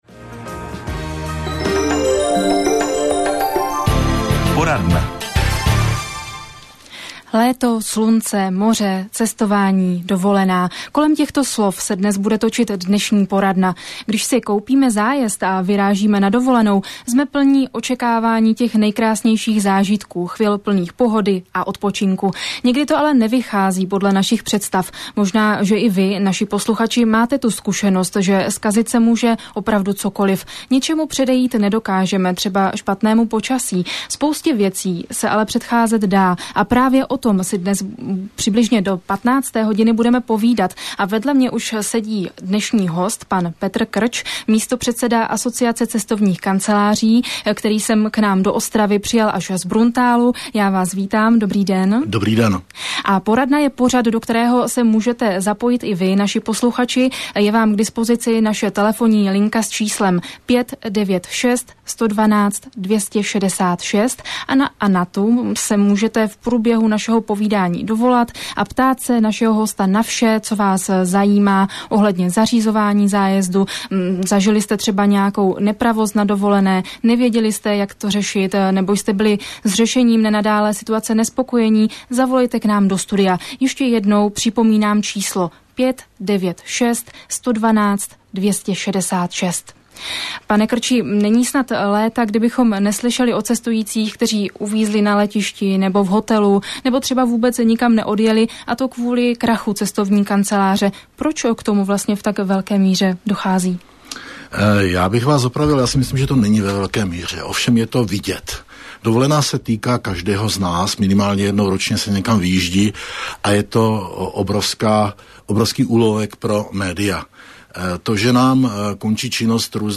Rozhlasová poradna
rozhlasova_poradna.mp3